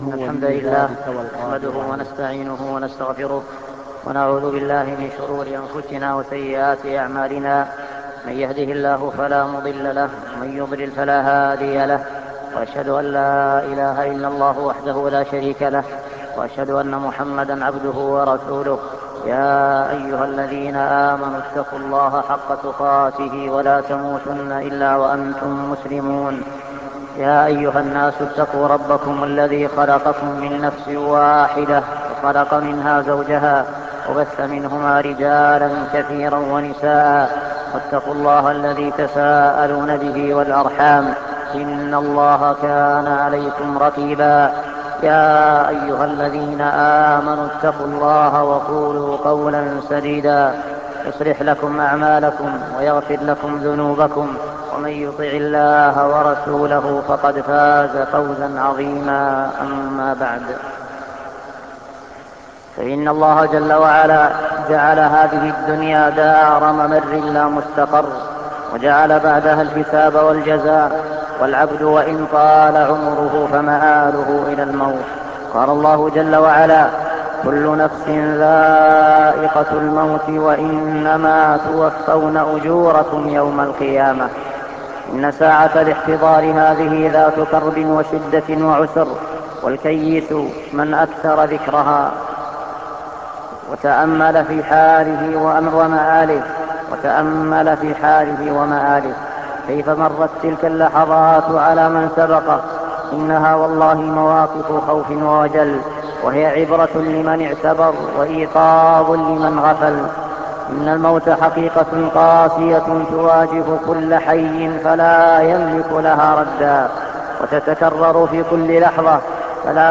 الإستعداد ليوم الرحيل - خطبة